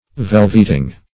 Search Result for " velveting" : The Collaborative International Dictionary of English v.0.48: Velveting \Vel"vet*ing\, n. The fine shag or nap of velvet; a piece of velvet; velvet goods.